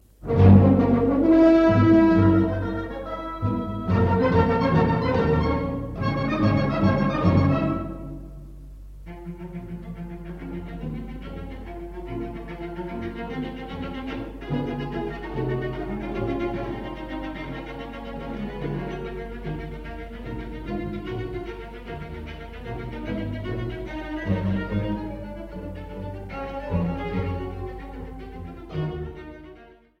Andante con moto**